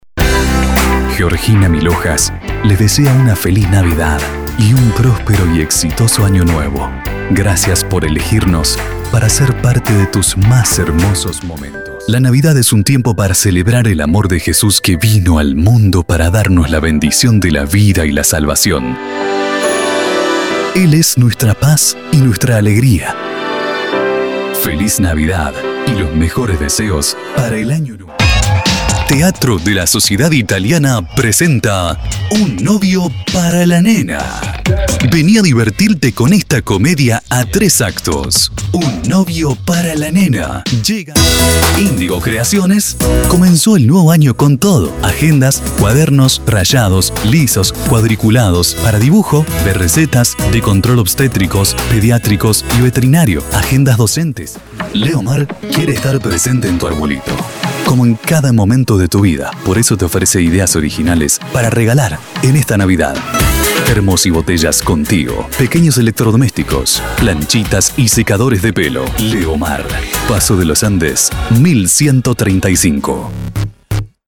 Grabacion de Publicidades, Locuciones en OFF, IVR
Castellano acento rioplatense 100%
Voz para grabaciones institucionales de ritmo medio.